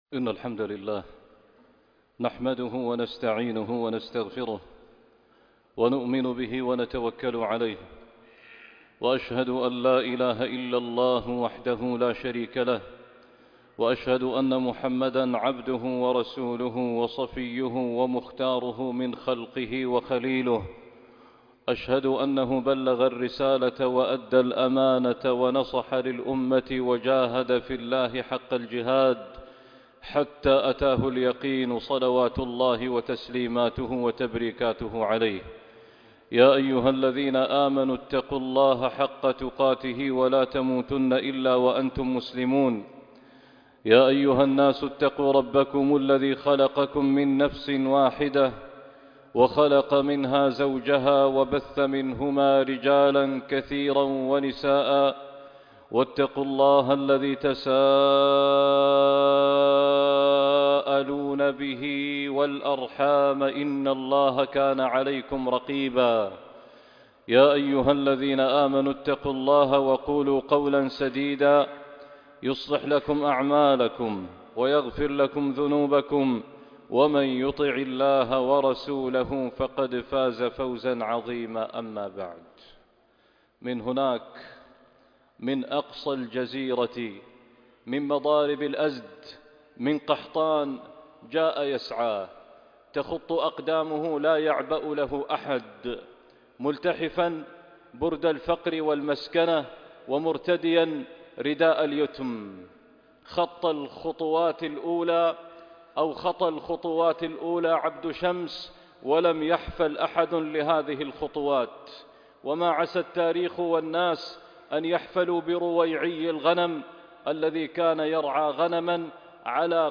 «الفتى الدَّوْسي» خطبة الجمعة ٣ ربيع الآخر ١٤٤٤